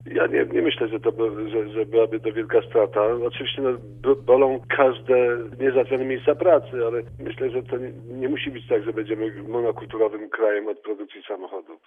Bardzo zabiegaliśmy o tę inwestycję, ale jeśli jej nie będzie, to nasza gospodarka nie zawali się - powiedział wiceminister gospodarki i pracy Maciej Leśny.